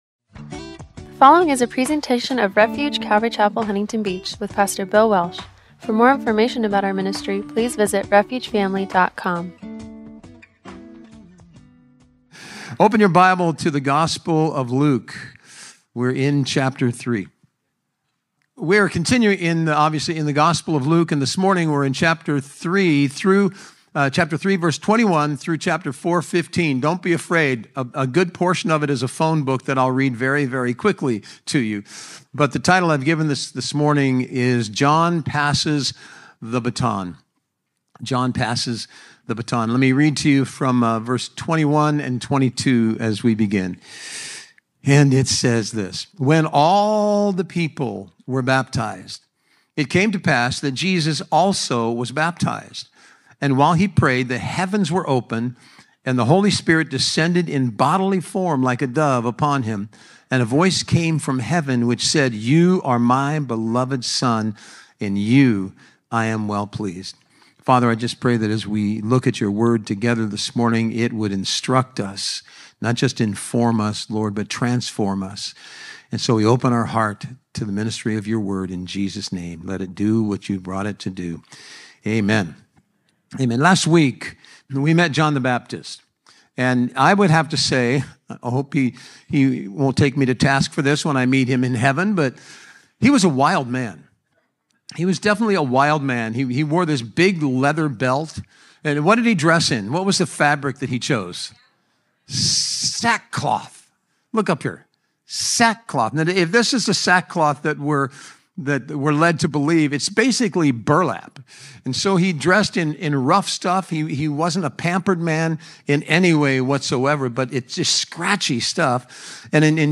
A study through Luke-That You May Know” – Audio-only Sermon Archive
Service Type: Sunday Morning